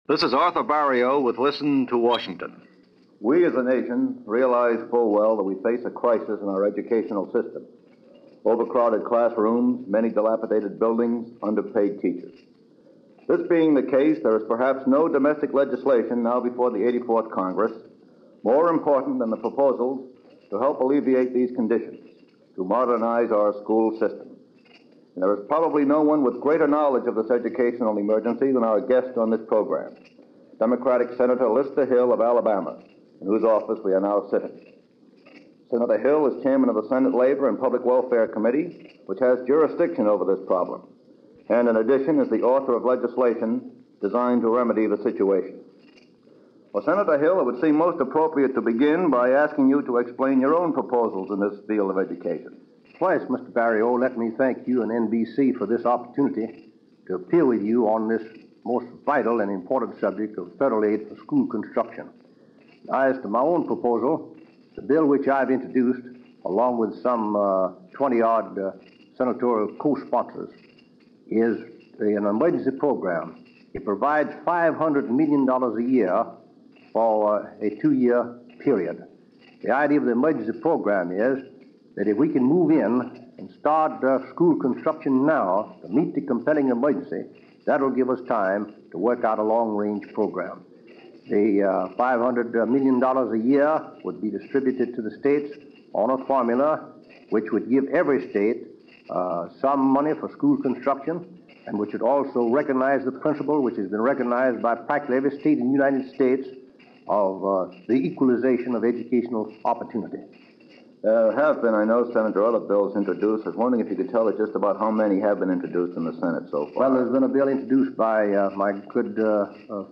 A Matter of Education - A Question Of Our Place In The World - 1955 - A discussion on the crisis in Education.
In this episode of the weekly radio news series Listen To Washington, Democratic Senator from Alabama Lester Hill, Chairman of the Senate Labor and Public Welfare committee, which was overseeing the situation and was also the author of bills seeking to remedy the crisis, talks about the crisis and what was being done about it.